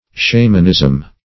Shamanism \Sha"man*ism\, n.